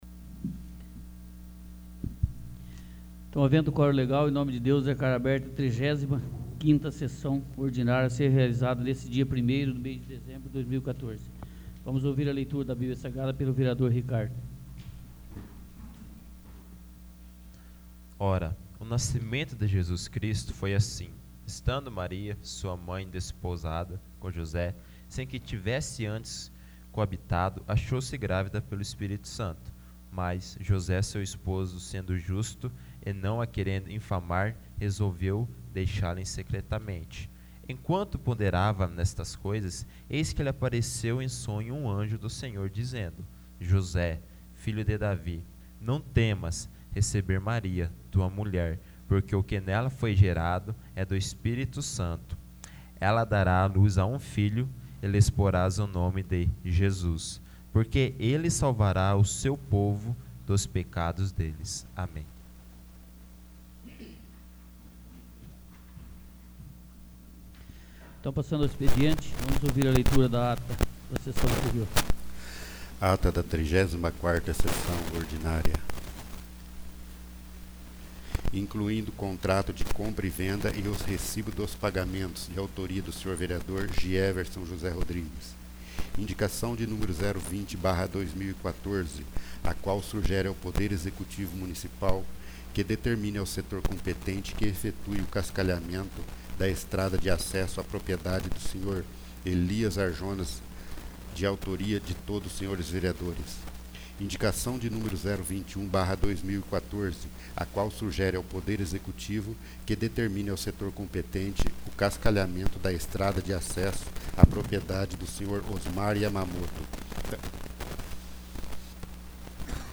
35º. Sessão Ordinária